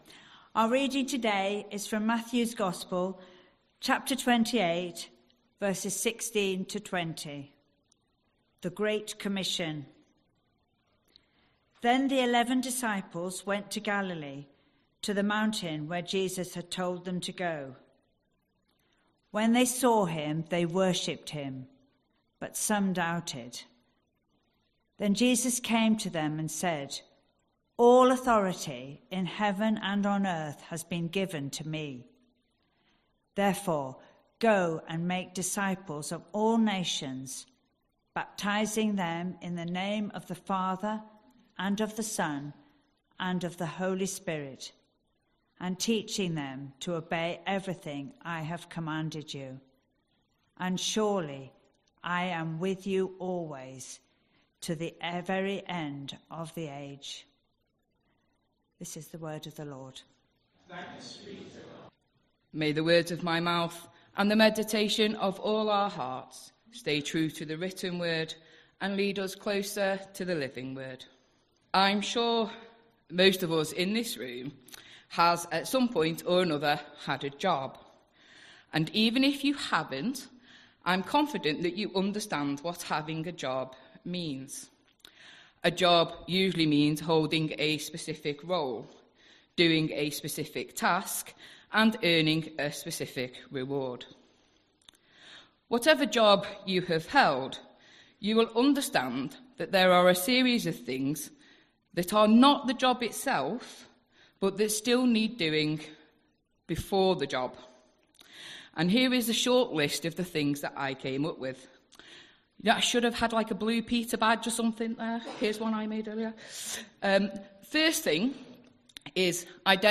23rd November 2025 Sunday Reading and Talk - St Luke's